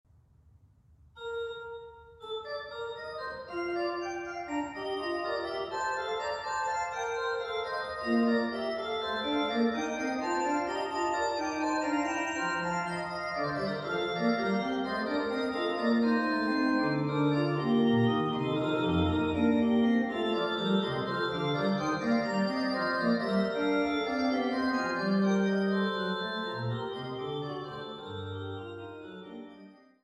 Praeludium und Fuge A-Dur